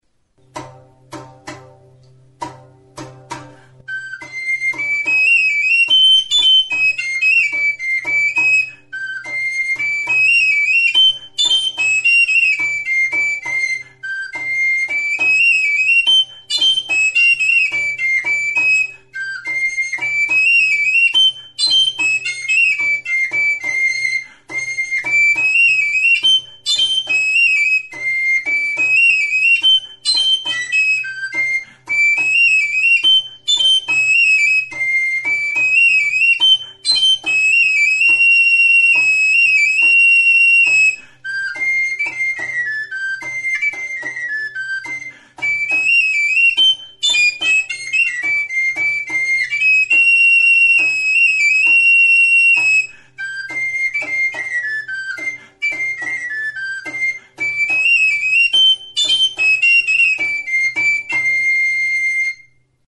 Instrumentos de músicaXIRULA; TXIRULA
Aerófonos -> Flautas -> Recta (de una mano) + flautillas
Grabado con este instrumento.
Hiru zuloko flauta zuzena da, ezpelezko txirula.